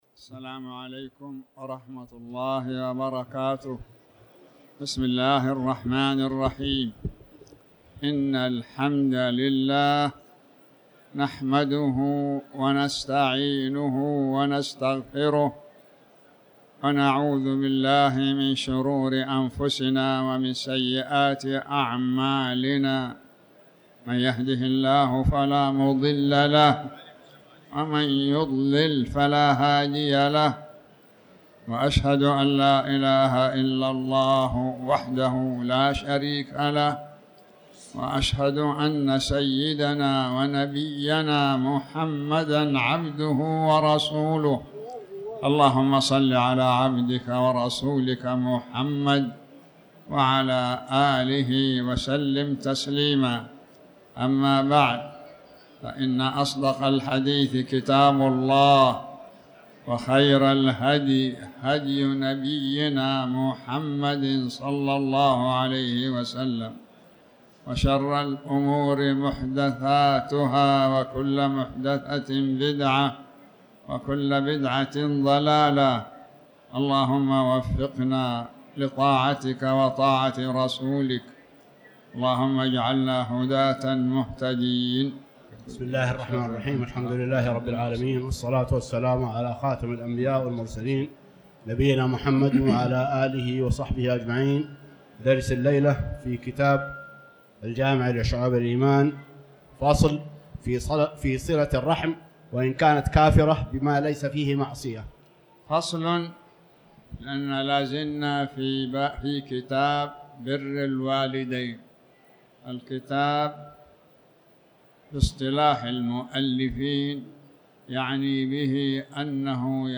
تاريخ النشر ١١ ربيع الثاني ١٤٤٠ هـ المكان: المسجد الحرام الشيخ